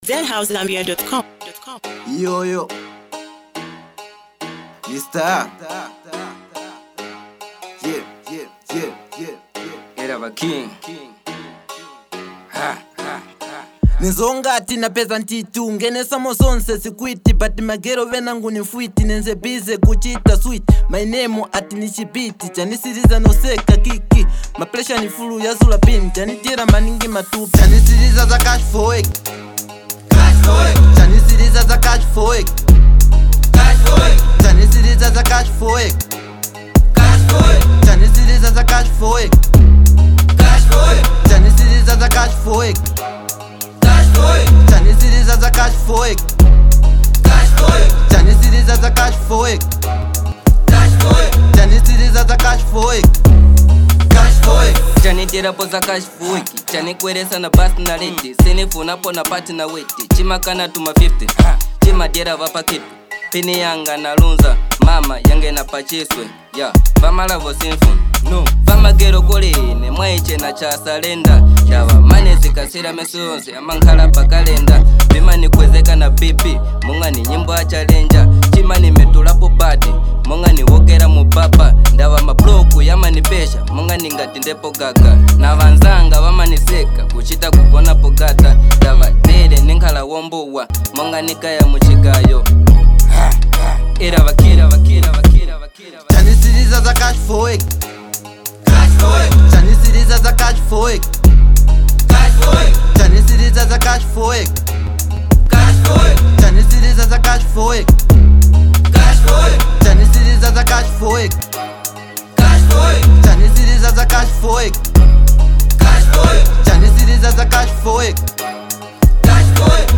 a hard-hitting hip-hop track